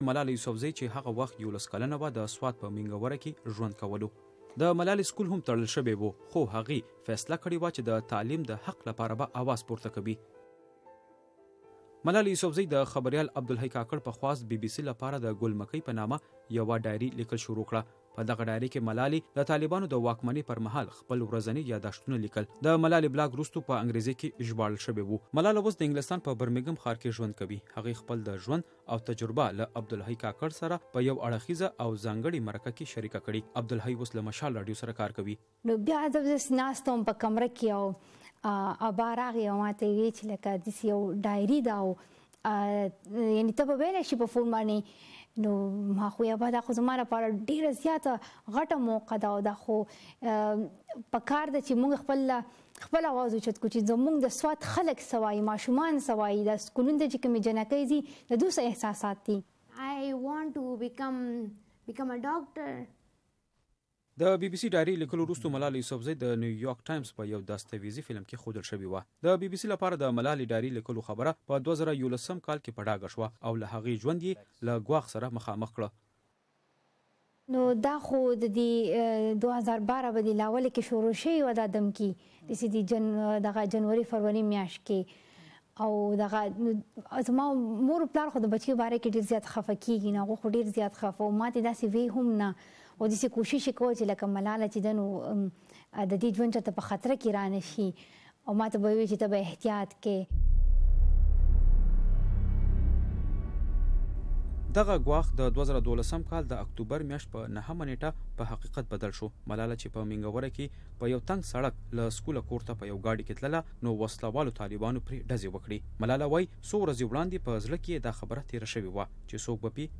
د ملالې یوسفزۍ بشپړه مرکه